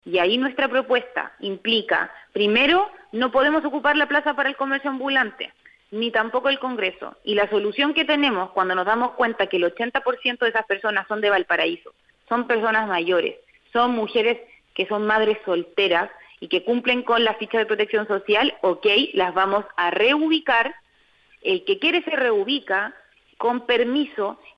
A pesar de las críticas, la alcaldesa de Valparaíso, Camila Nieto, en conversación con Radio Bío Bío, defendió la propuesta del municipio e insistió en la reubicación.